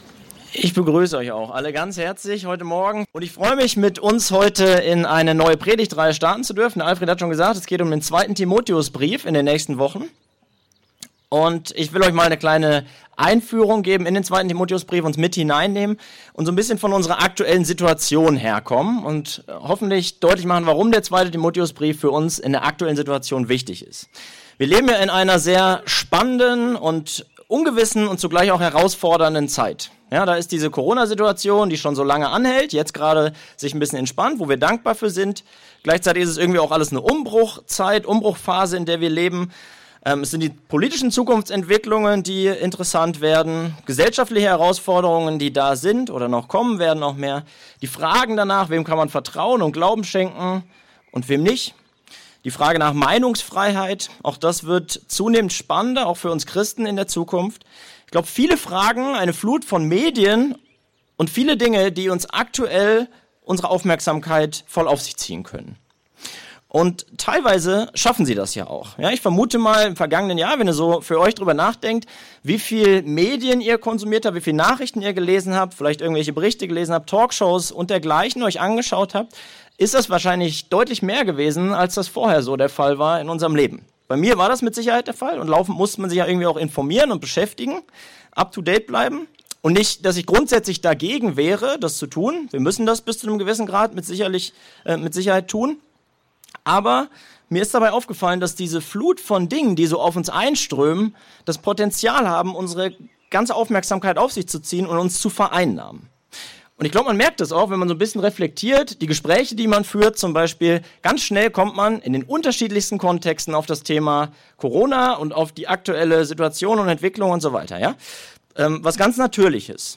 predigte am Sonntag, den 13. Juni über 2. Timotheus 1, 1-8 mit dem Thema: „Im Auftrag des Herrn“